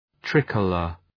Προφορά
{‘tri:kələr}